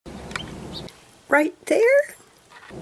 Throughout much of the video there will be a clip of Songbird speak followed by my attempt to imitate the Songbird accent while speaking what seem to me to be the words they just spoke.